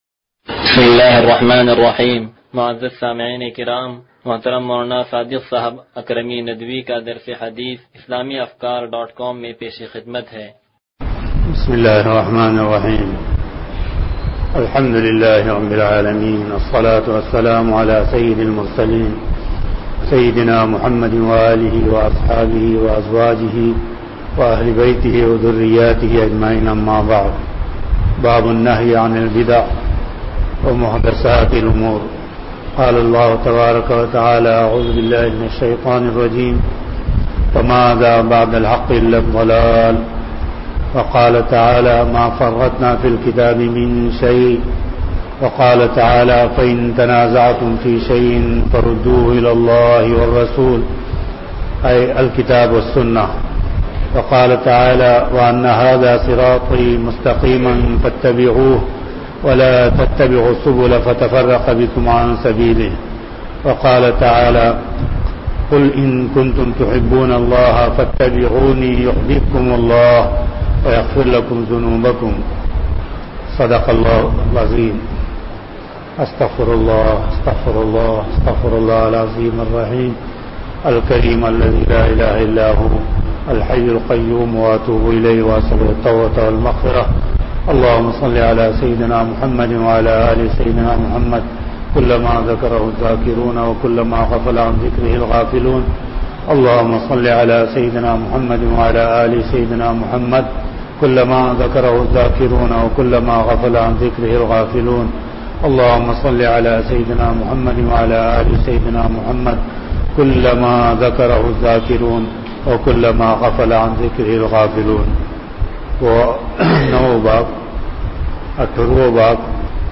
درس حدیث نمبر 0181